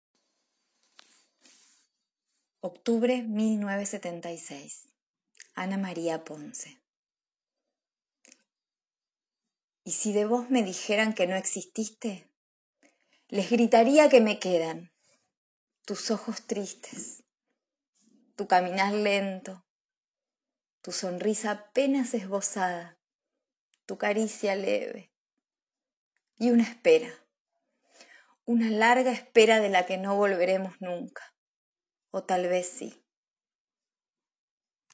lee el poema «Octubre 1976